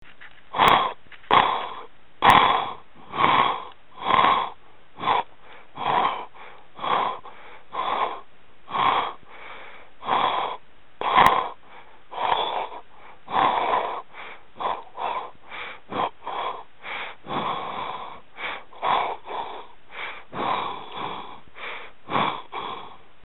Â The constant air flow in and out because of breathing is modulated by your vocal cords in silent speaking when you think and it is the recording of this modulation that creates ‘speak thinking’.